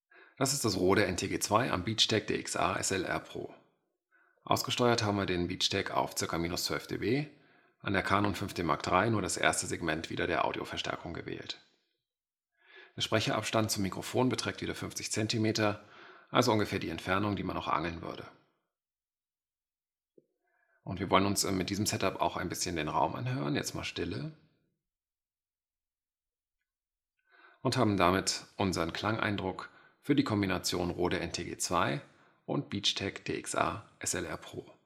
Und hier die Audio-Files mit einem leichten EQ und stärkerer Rauschunterdrückung:
Rode NTG-2 via Beachtek DXA-SLR PRO an Canon EOS 5D Mark III + EQ + DeNoise + EQ + DeNoise